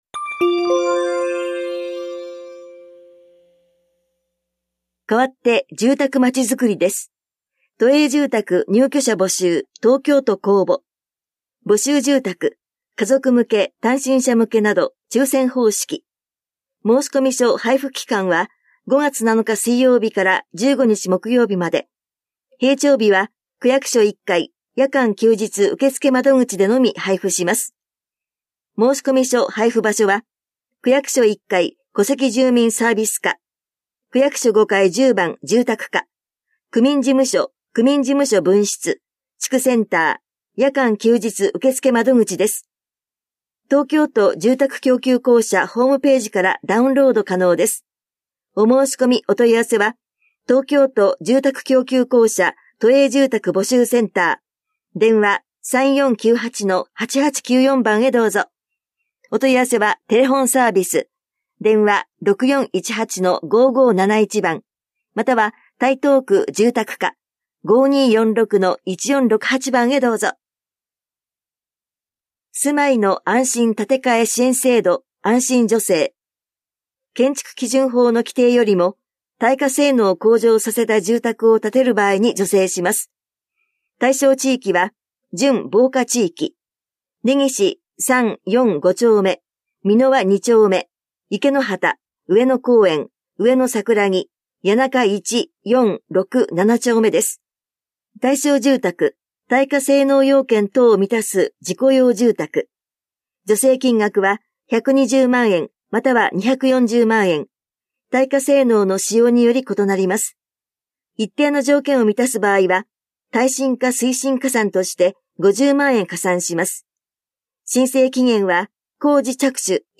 広報「たいとう」令和7年4月20日号の音声読み上げデータです。